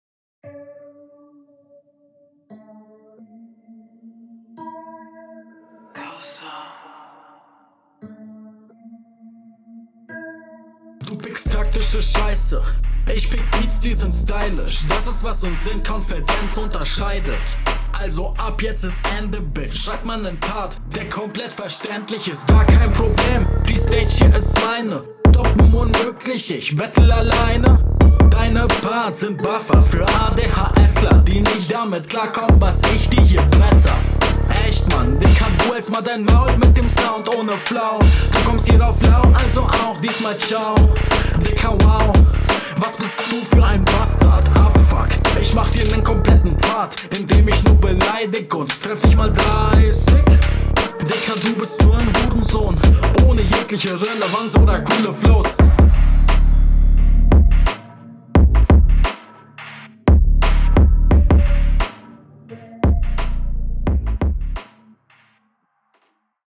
Kommst auf dem Beat sehr cool. Finde du hast auch eine schön arrogante Attitüde.